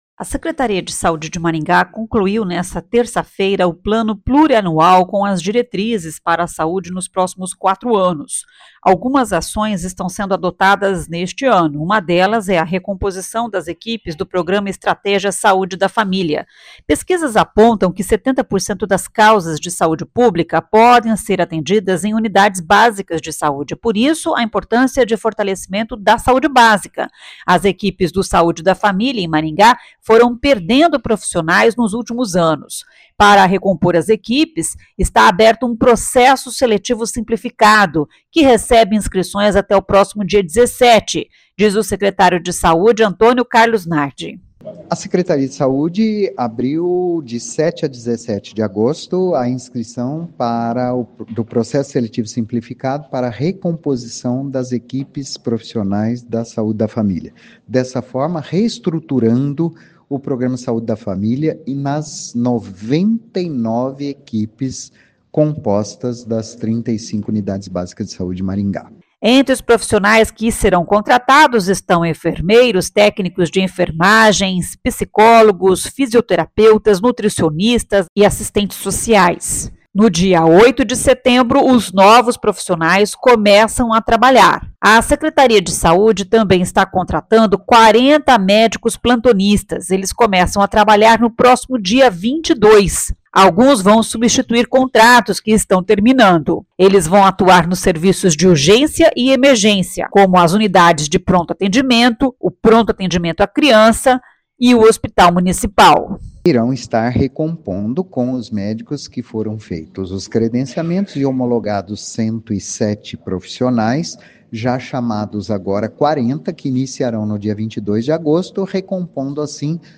Para recompor as equipes, está aberto um processo seletivo simplificado que recebe inscrições até o próximo dia 17, diz o secretário de Saúde, Antônio Carlos Nardi.